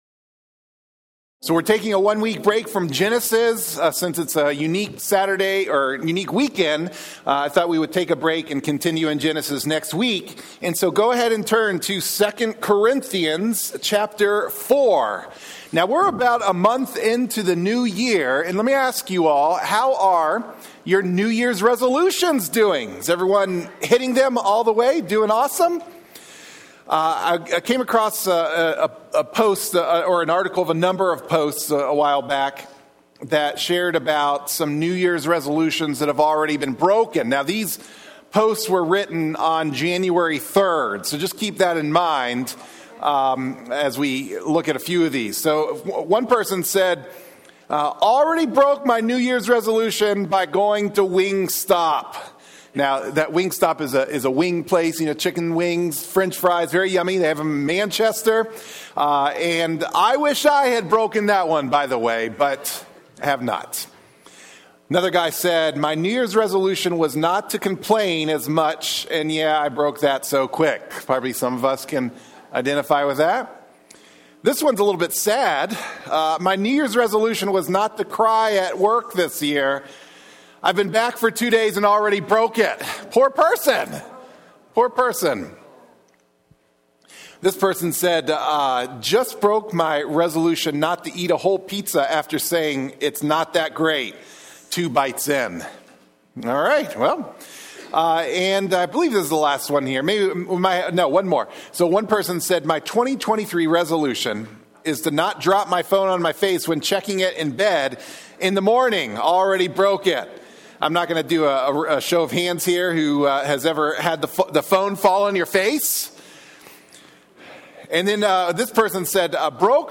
Sermon-2-8-25-MP3-for-Audio-Podcasting.mp3